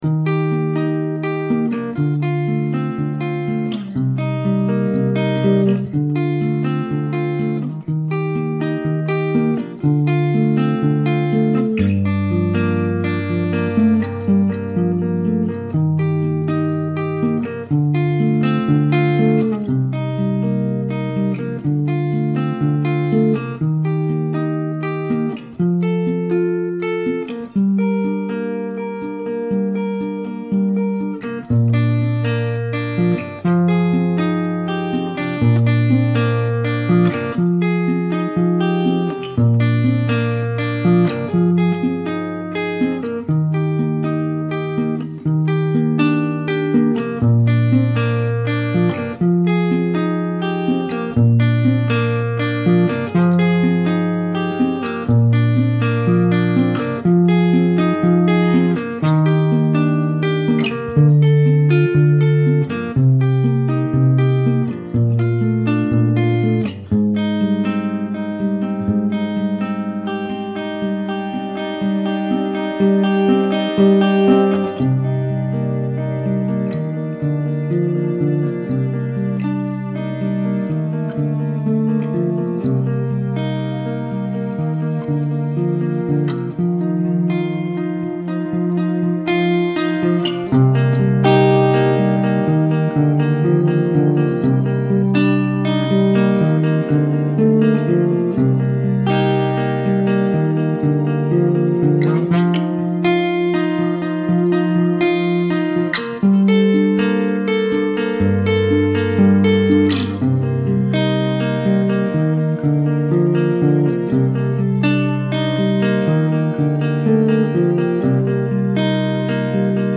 Testing viola & cello sounds.. ok what?
Ok What Acoustic Home Test Mx2 ok_what_acoustic_home_test_mx2.mp3 Ok what, testing testing The Available homerecording Category: Rehearsal recordings Date: February 1, 2012 4.9 MiB 12 Downloads Details…
ok_what_acoustic_home_test_mx2.mp3